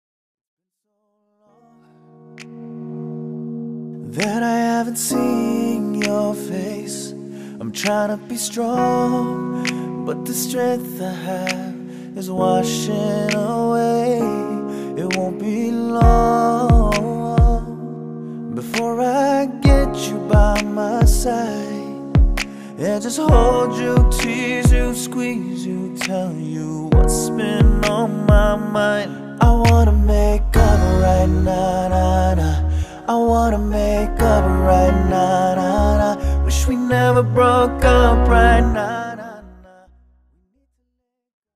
• Качество: 192, Stereo
красивый мужской голос
Cover
RnB
нежные
Нереально красивый кавер на знаменитую песню